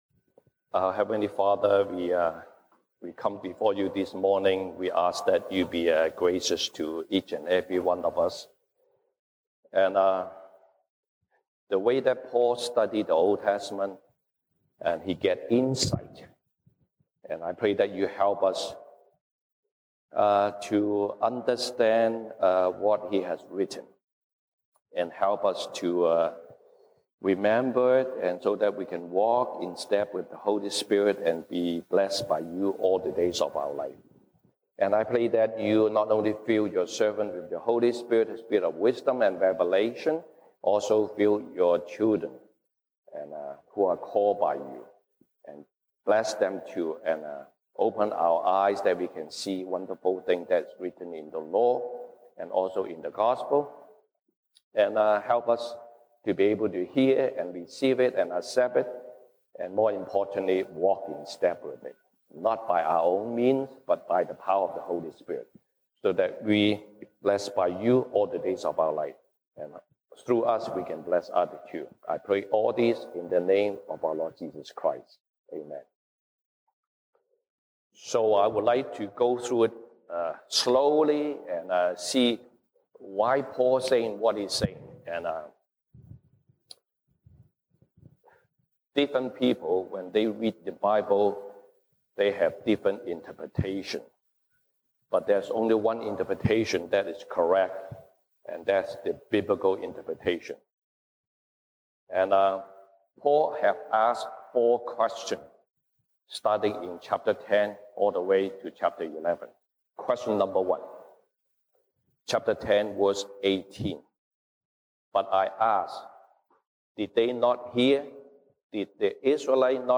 西堂證道 (英語) Sunday Service English: Have a proper response to God’s discipline